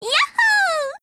贡献 ） 协议：Copyright，其他分类： 分类:语音 、 分类:少女前线:P2000 您不可以覆盖此文件。